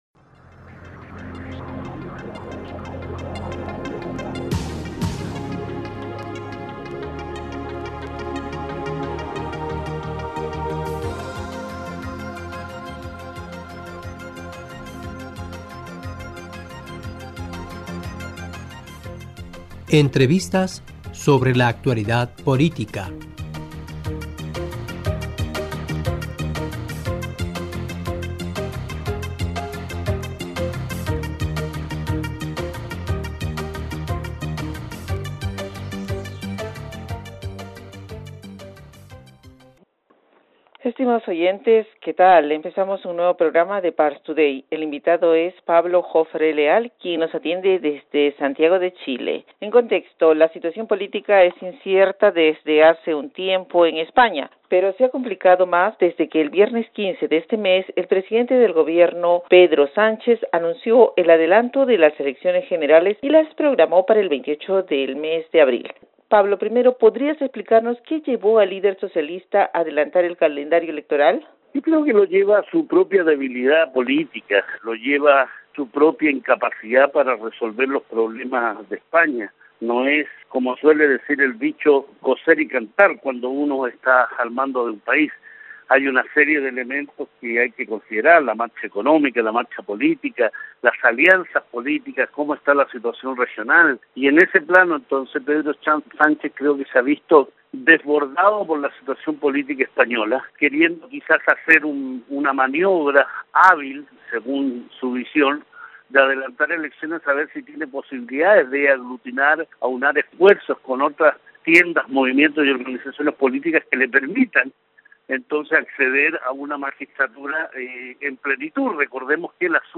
Parstoday- E: Estimados oyentes ¿qué tal) empezamos un nuevo programa de Parstoday.